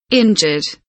injured kelimesinin anlamı, resimli anlatımı ve sesli okunuşu